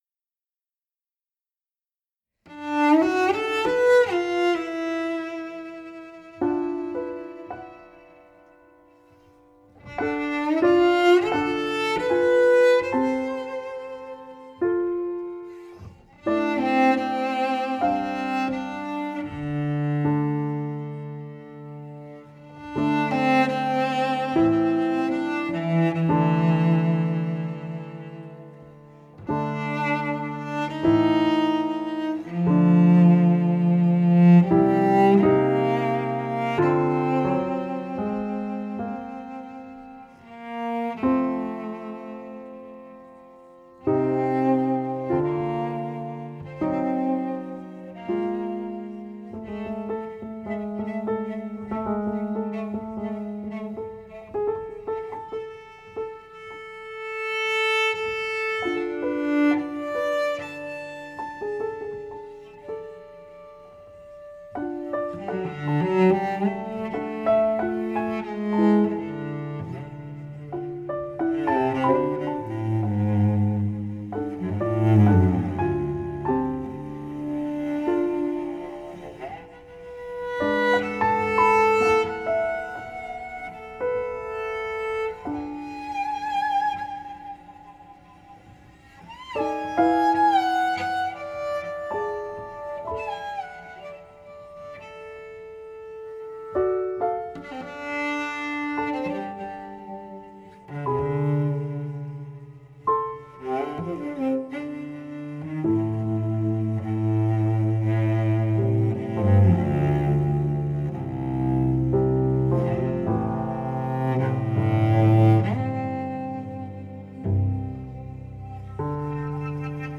avant-jazz/third stream/singer-songwriter
piano & voice
cello